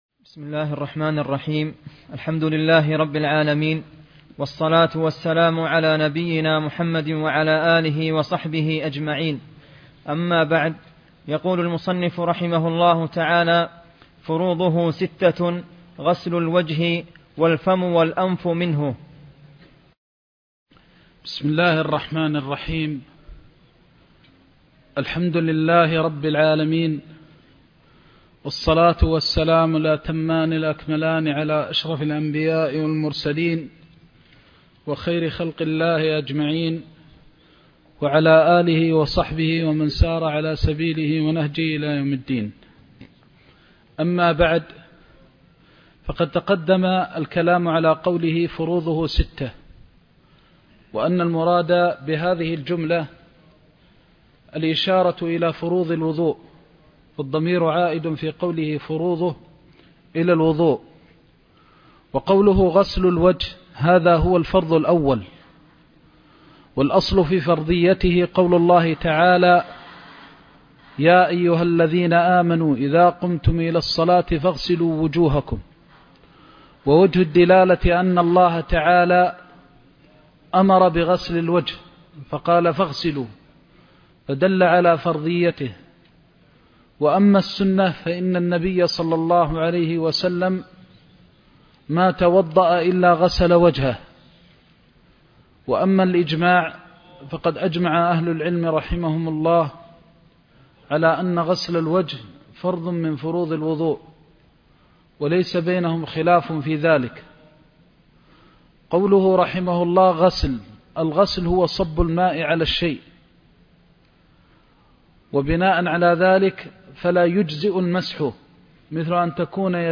زاد المستقنع كتاب الطهارة (14) درس مكة